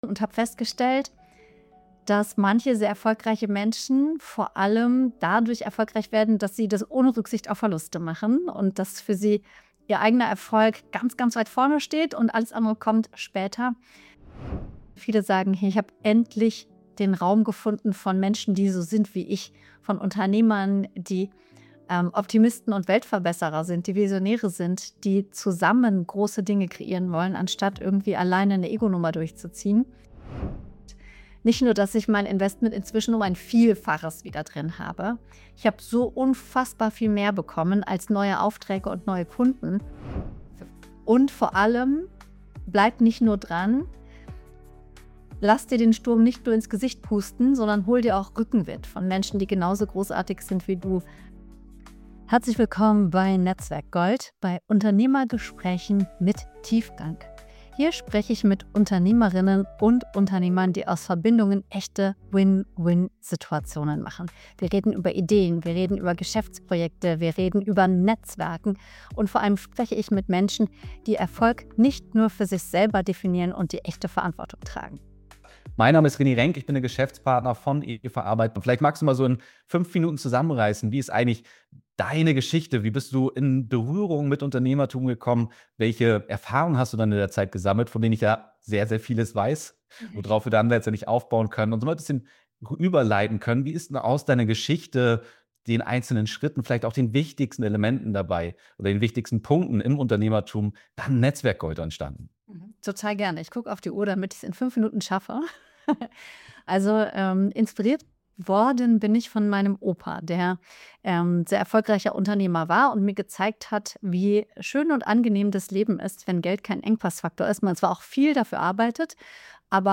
Willkommen beim NetzwerkGold Podcast – Unternehmergespräche mit Tiefgang.